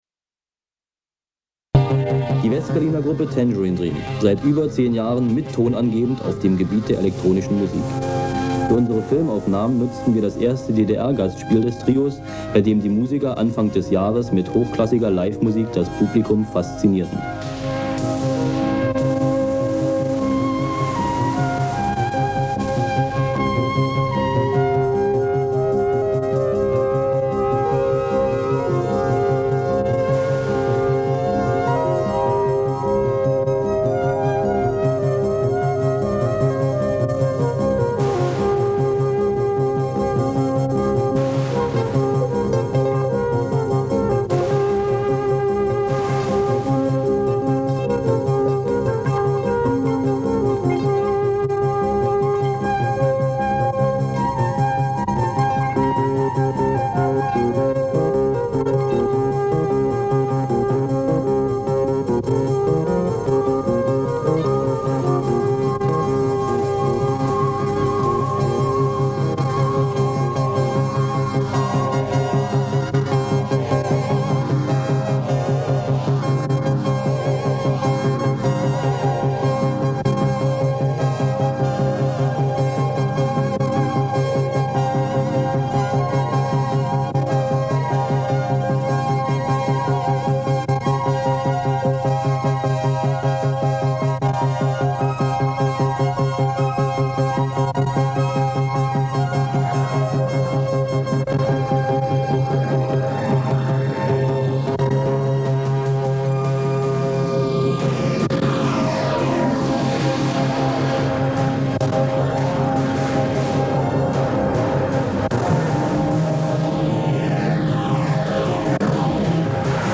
td_interview_gdr1980.mp3